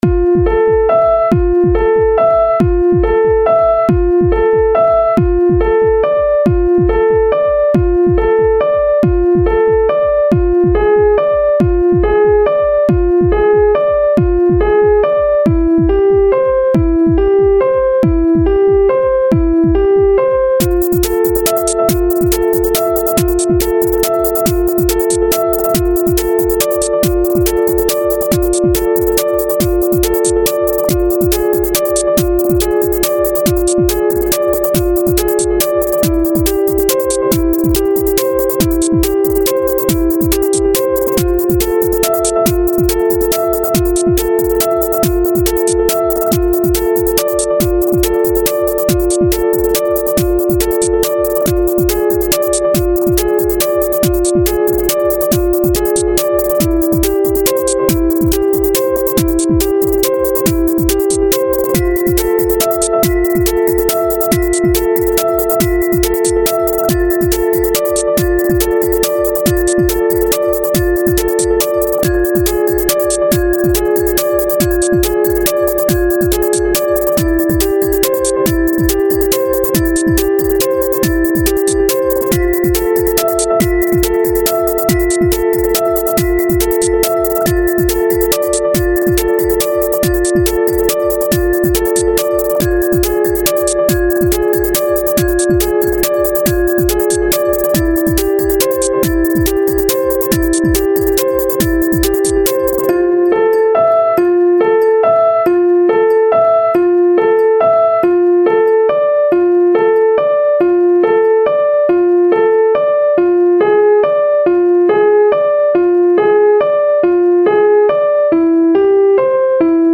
피아노 코드 진행은 그대로 구요 ㅋㅋㅋㅋㅋ
드럼이나...다른 소스 조금 더넣구요;;
이 곡의 스따일은 그냥... 일렉트로닉인데 연주곡인 곡 있잖아요 ㅋㅋㅋ
쿵치타쿵치타쿵치타 식으로 지금비트가 가잖아요.
미뉴에트나 왈츠가 이 리듬입니다.